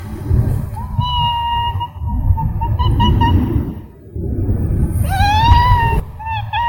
小猫叫的声音
描述：喵叫
标签： 猫咪 小猫叫声 可爱的猫叫